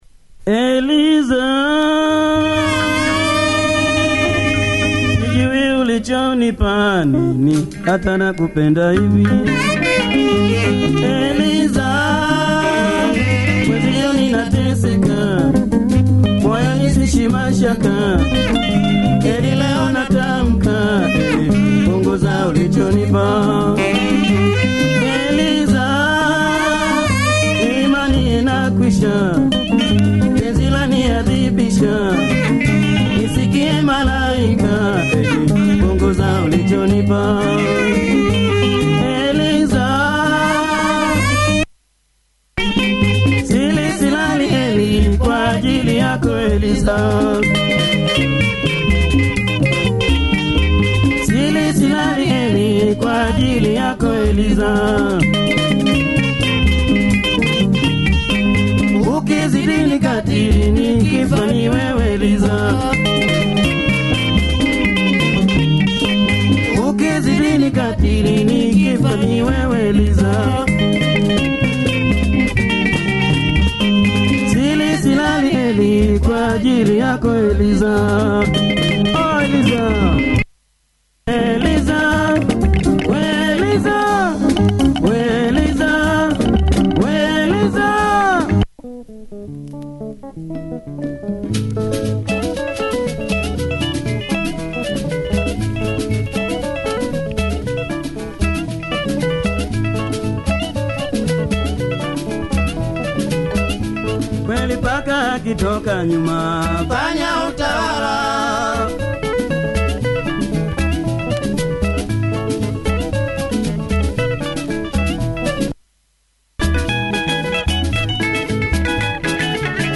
popular Tanzanian band